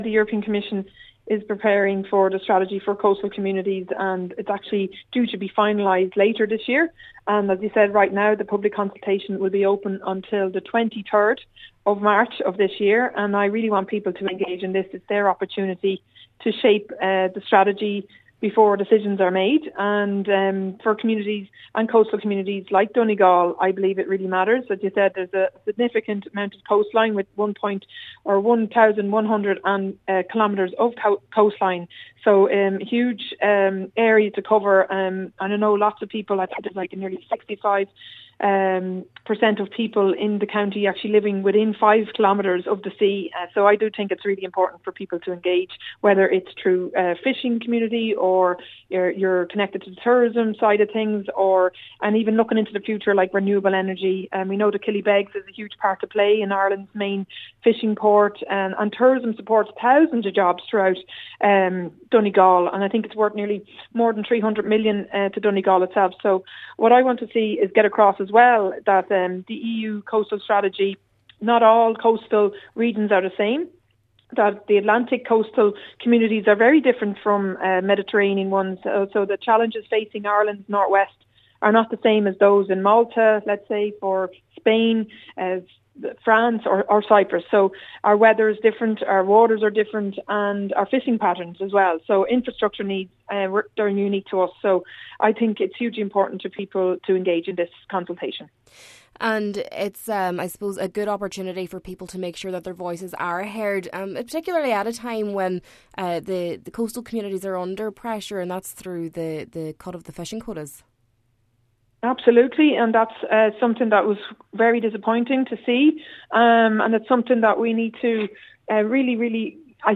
Carberry added that, especially in light of recent cuts to fishing quotas, it is important for the public to make their voices heard: